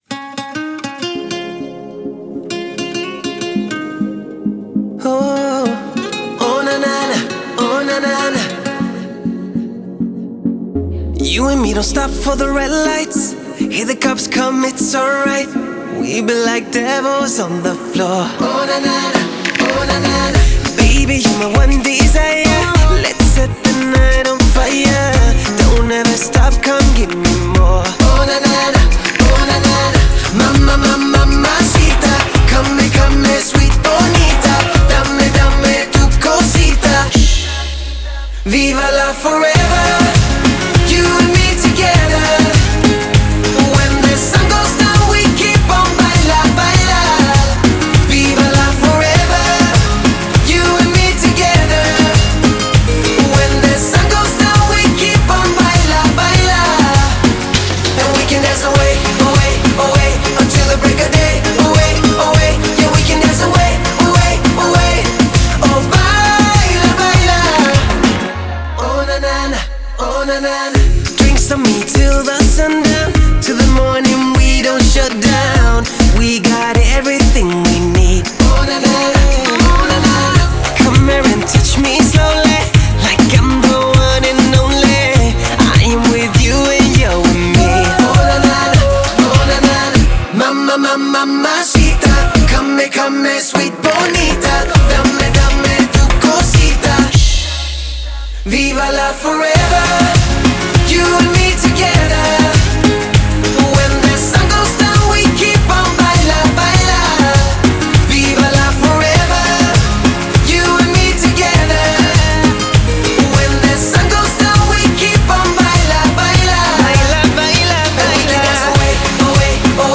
это зажигательная танцевальная композиция в жанре латин-поп